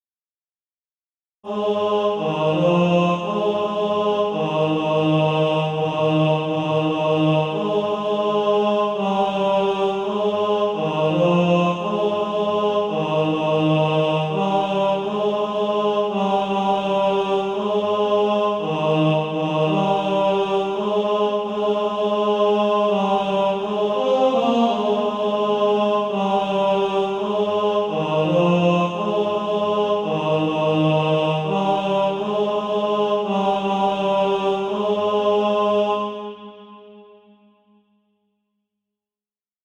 (SATB) Author
Tenor Track.
Practice then with the Chord quietly in the background.